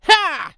CK发力04.wav
CK发力04.wav 0:00.00 0:00.58 CK发力04.wav WAV · 50 KB · 單聲道 (1ch) 下载文件 本站所有音效均采用 CC0 授权 ，可免费用于商业与个人项目，无需署名。
人声采集素材/男2刺客型/CK发力04.wav